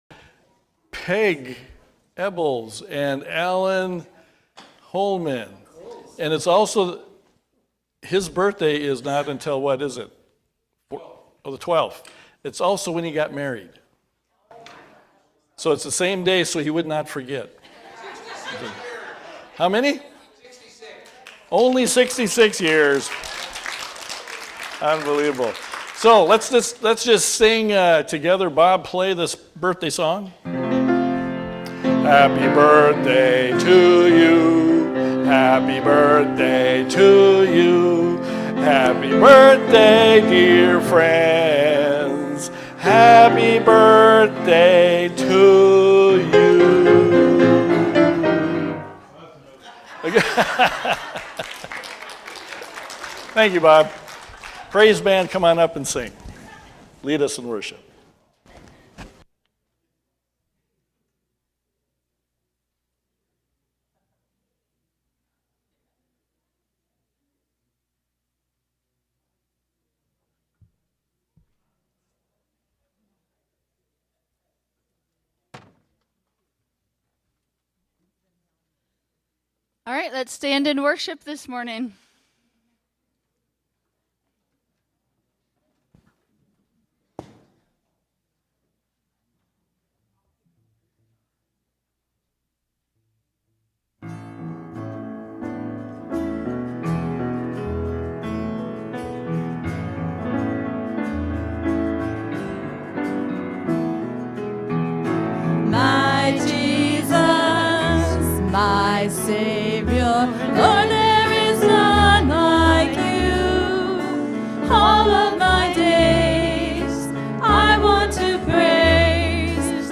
Worship-June-8-2025-Voice-Only.mp3